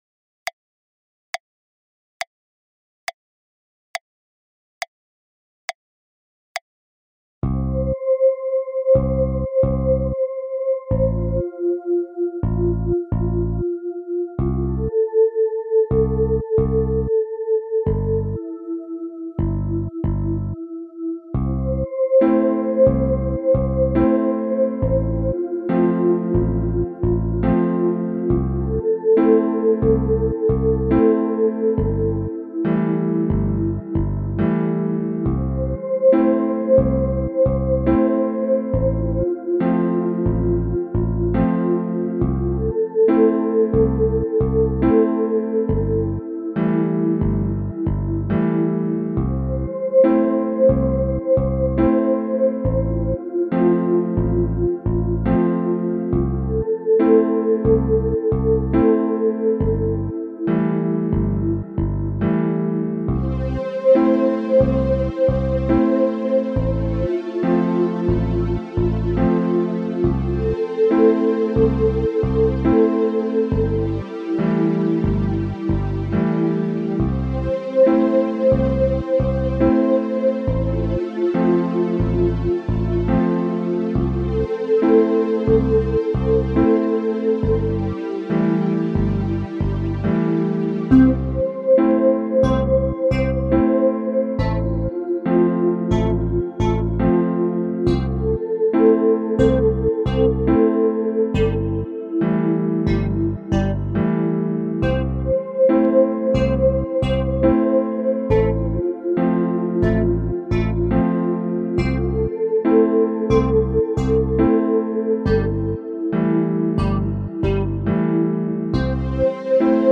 Ausgehend von diesen Fred: KLICK hätte ich einen Song (ohne Gesang), den ich bei Interesse zur Verfügung stellen würde.